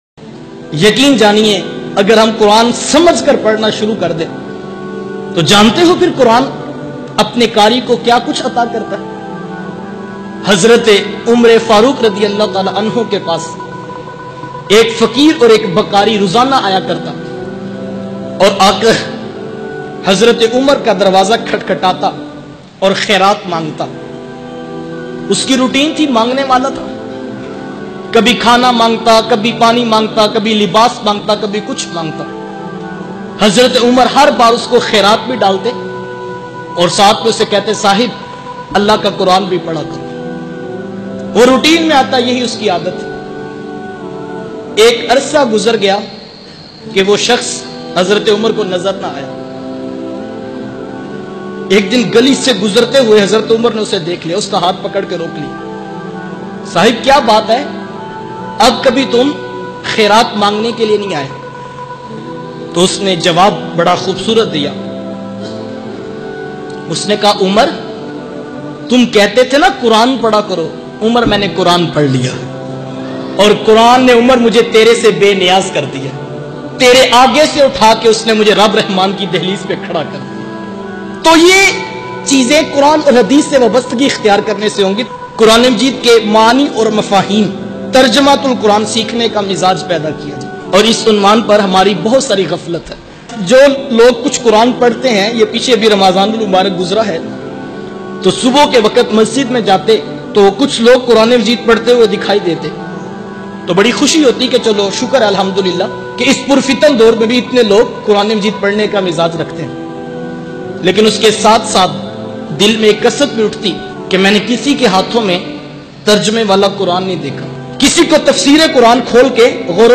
Fakir ka vo roz roz mangna or ek din Quran ki talim behtreen bayan mp3